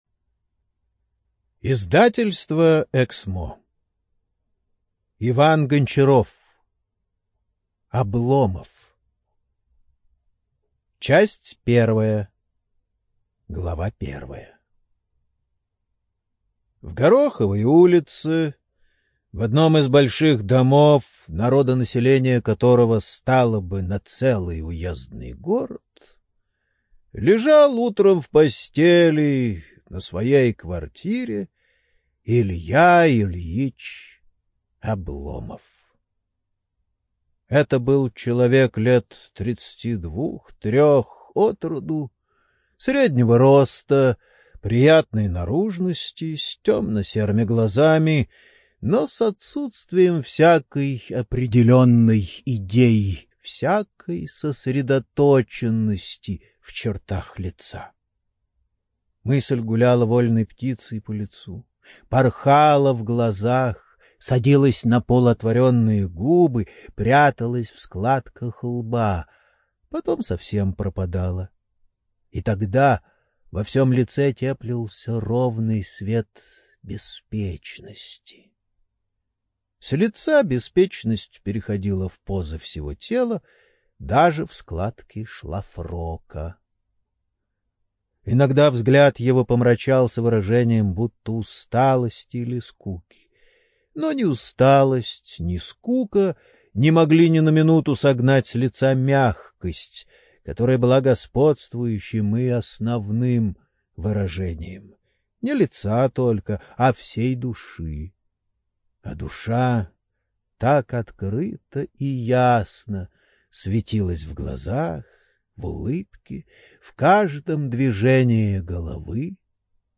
ЖанрКлассическая проза
Студия звукозаписиЭКСМО